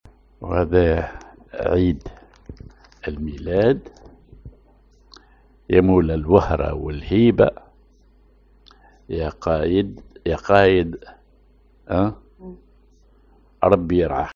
Maqam ar بياتي
genre نشيد